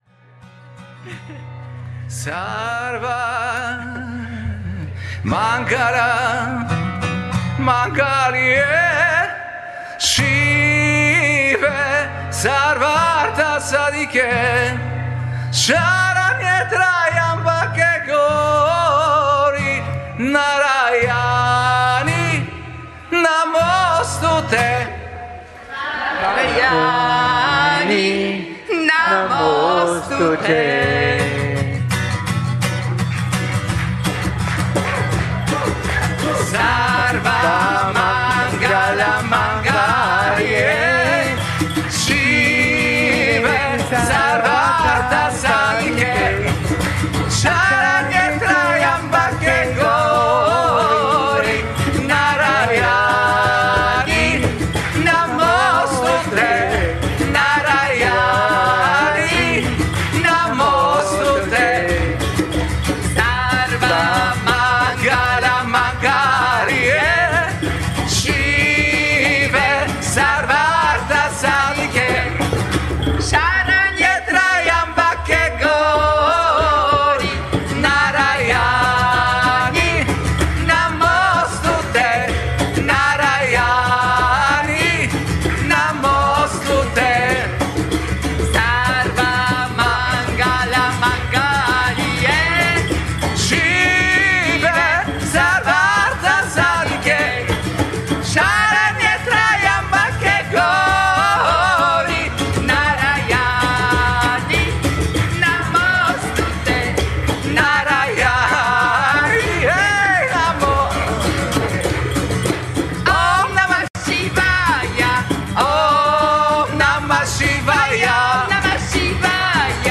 gesungen von Gruppe Mudita während eines Samstagabend Satsangs bei
ein Mangala Charana Mantra.
Mantra-/ Kirtan-Singen ist eine wunderbare